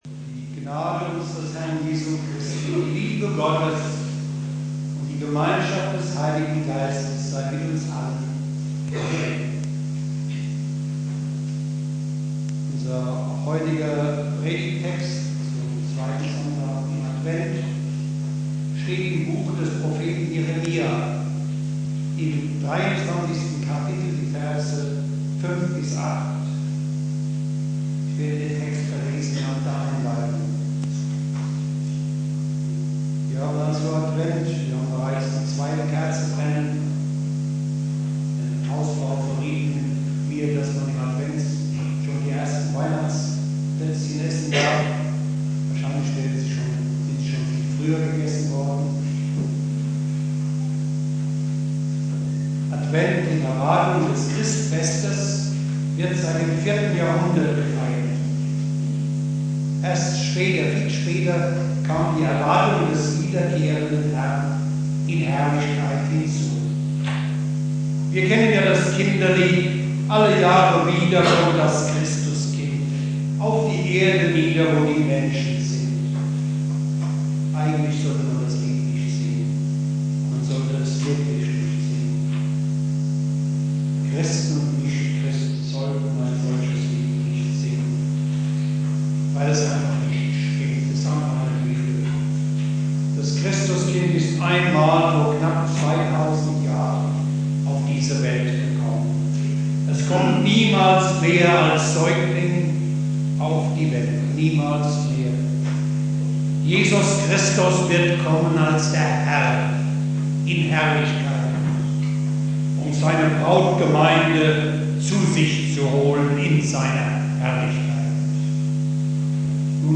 Predigt
2.Advent